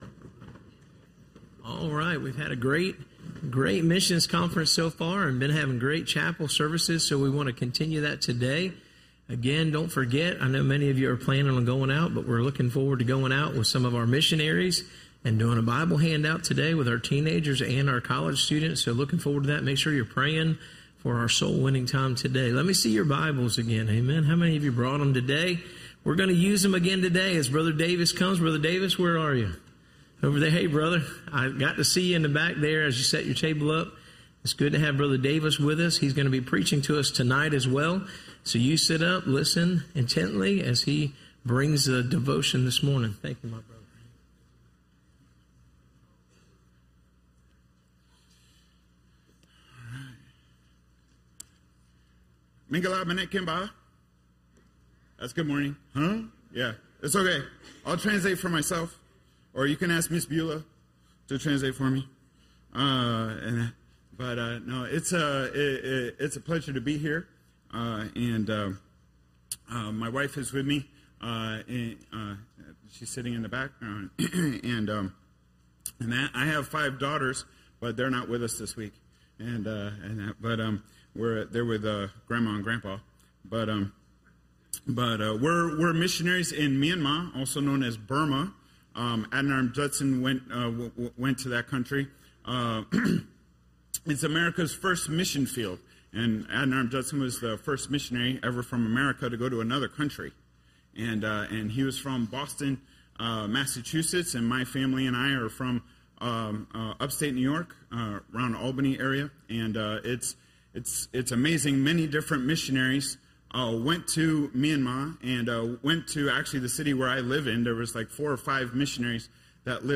Listen to Message
Service Type: Missions Conference